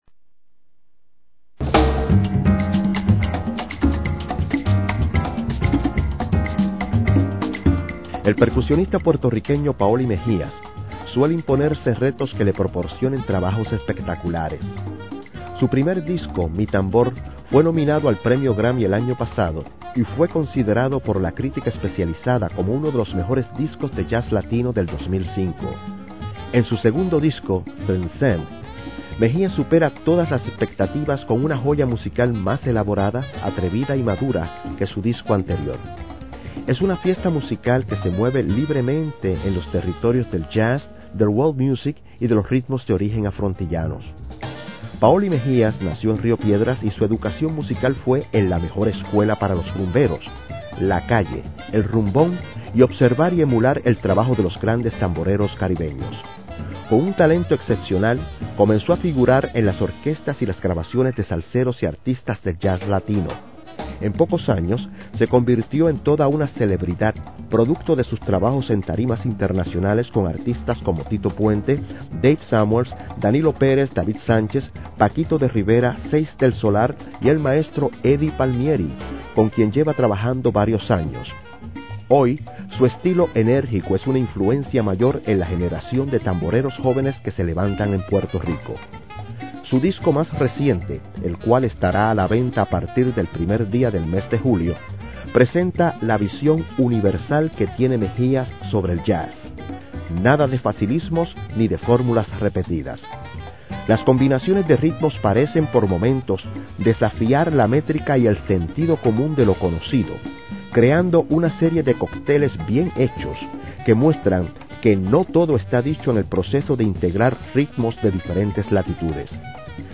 saxophones
piano
bass
drums
percussion